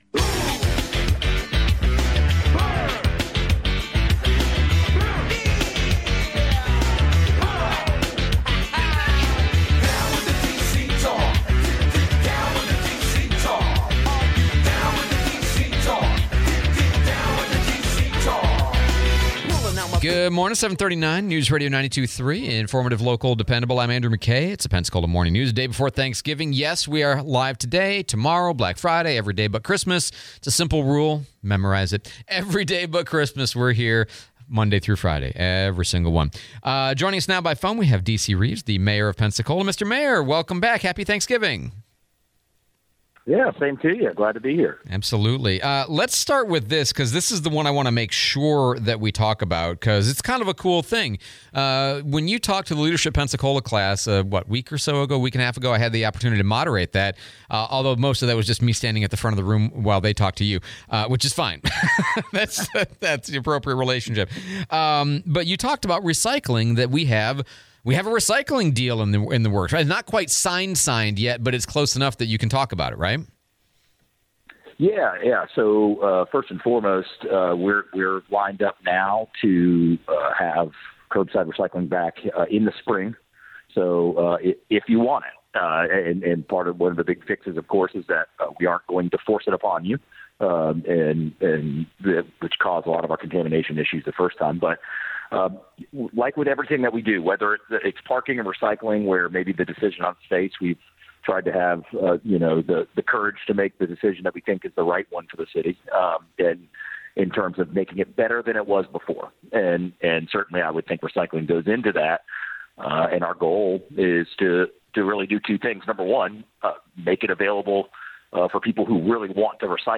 11/26/24 Mayor DC Reeves Interview